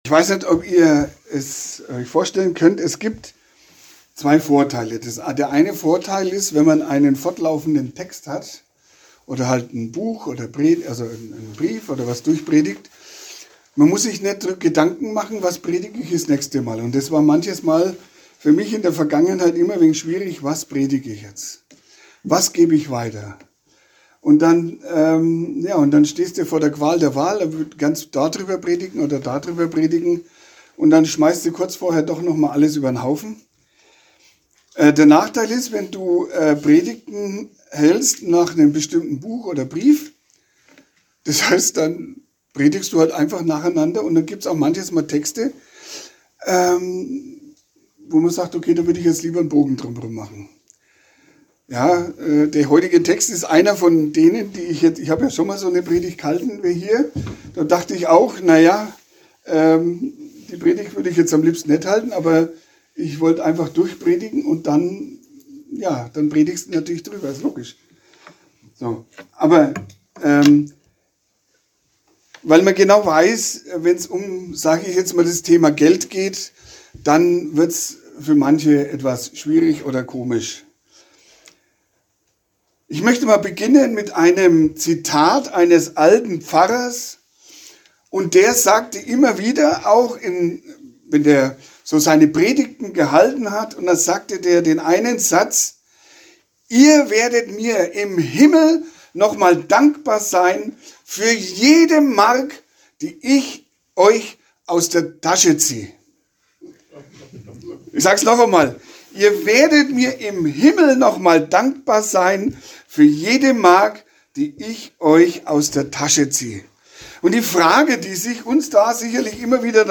2-6 Predigt-Archiv / Download – Deutsche Gemeinde-Mission e.V.